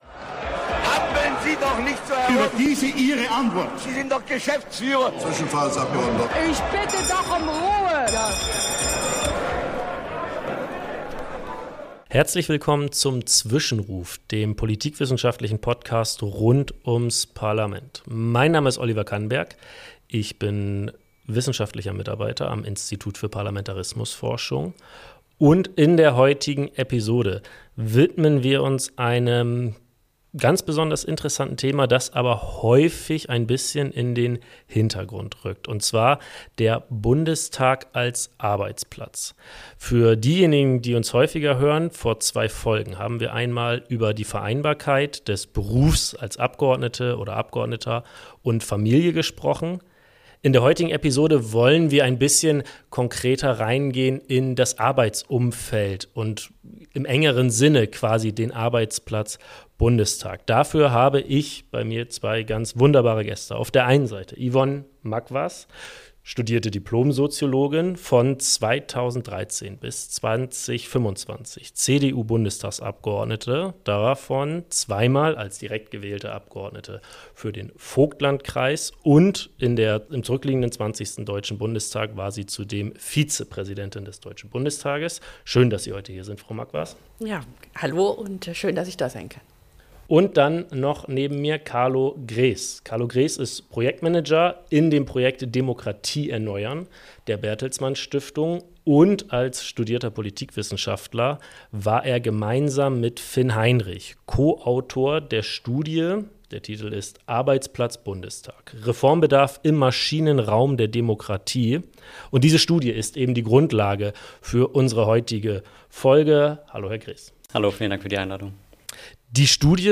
Wenn wir mit unseren Gästen aus Politik, Wissenschaft und Journalismus über Themen rund ums Parlament sprechen, verbinden wir politikwissenschaftliche mit praktischen, politischen Perspektiven.